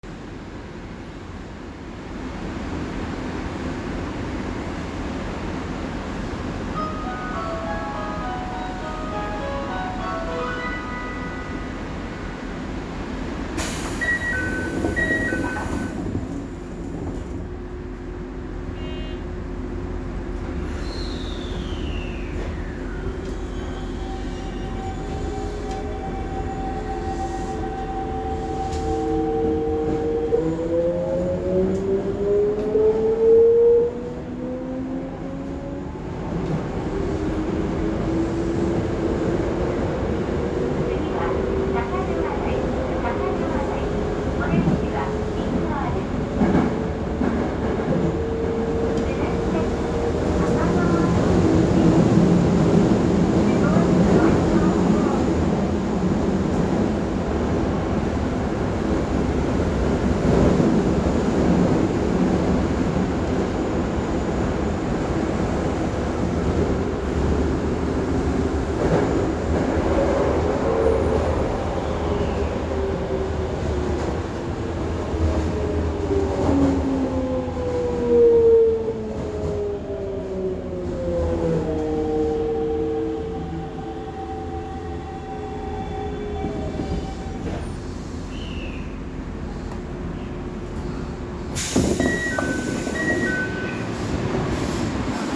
・5300形走行音
日本中の数多くの鉄道車両の中において、トップクラスに派手な音を立てているであろう5300形。近鉄に似たようなモーター音の車両も在籍していますが、起動音が異なります（とはいえ、VVVFの改修前は起動音も同じ編成がちらほら在籍していました）。その上、音量が大きく音割れも酷く、よく言えば豪快、悪く言えば喧しい事この上ない車両と言えます。地下でも地上でも走行音は賑やかで、都営線内で入る自動放送もよく聞こえません。…まぁでも、それが5300形らしいような気もします。